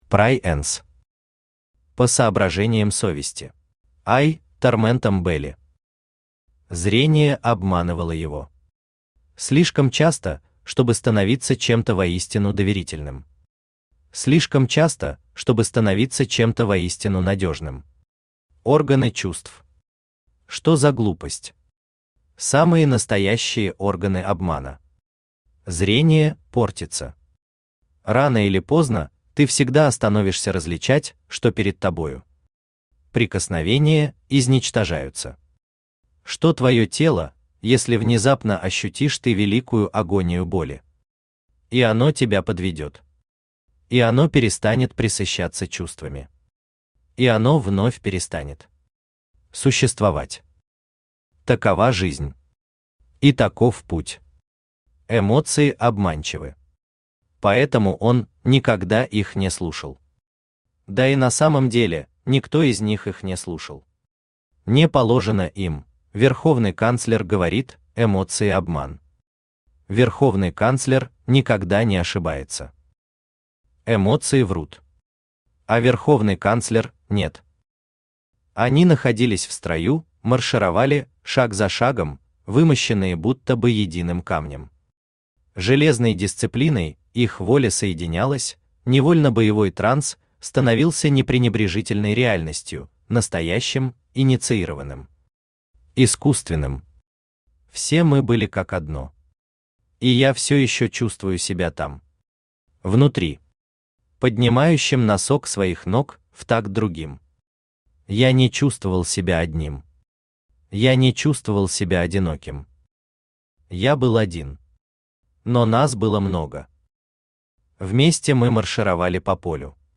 Аудиокнига По Соображениям Совести | Библиотека аудиокниг
Aудиокнига По Соображениям Совести Автор Prai'ns Читает аудиокнигу Авточтец ЛитРес.